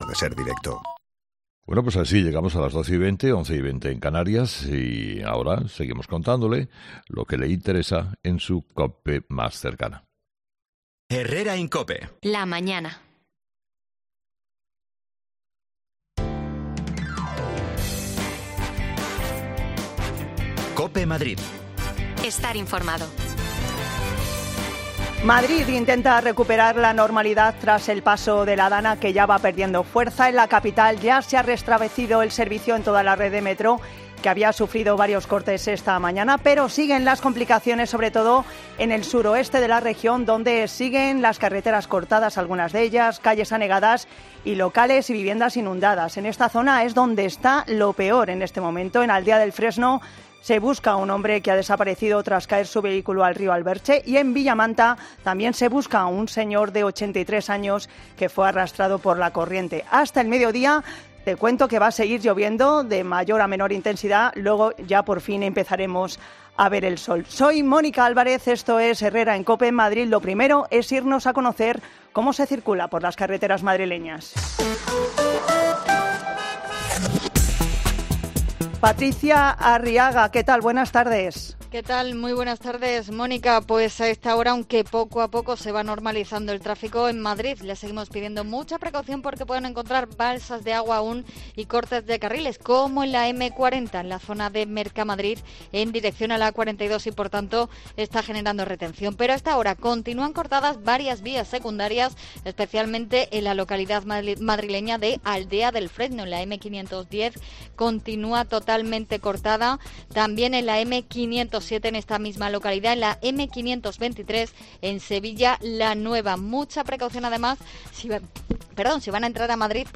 Nos acercamos a El Alamo para conocer la última hora y hablar con los vecinos
Las desconexiones locales de Madrid son espacios de 10 minutos de duración que se emiten en COPE , de lunes a viernes.